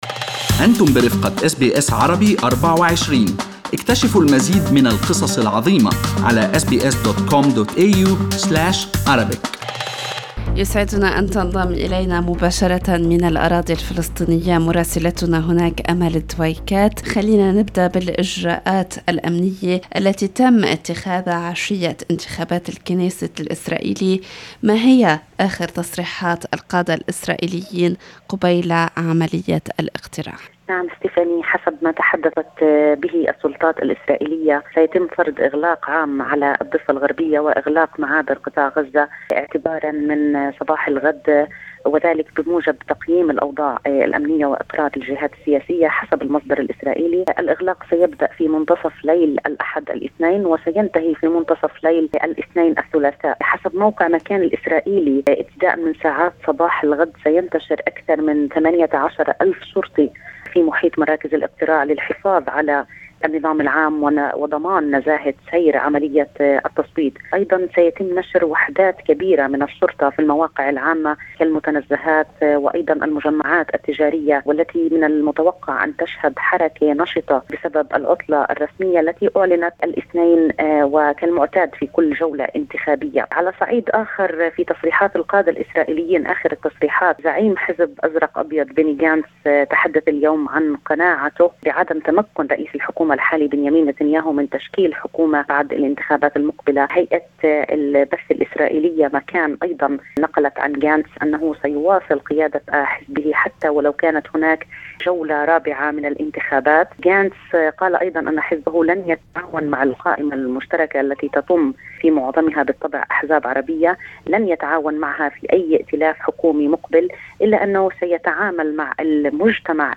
التقرير الأسبوعي